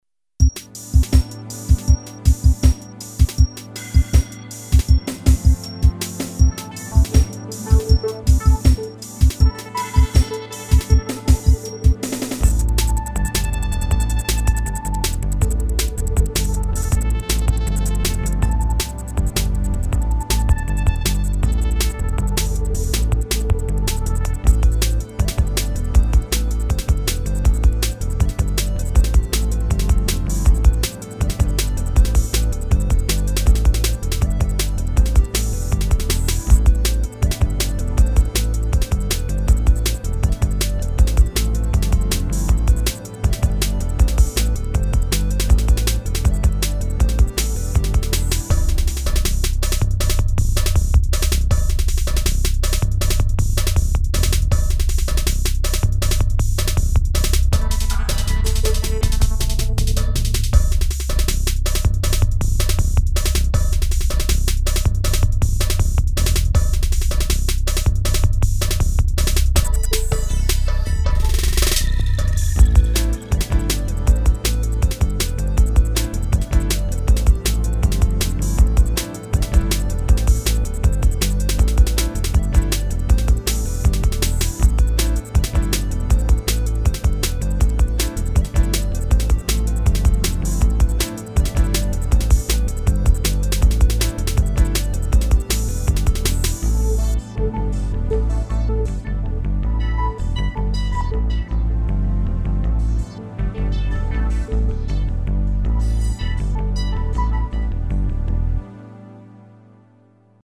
Class: Synthesizer
Synthesis: PCM rompler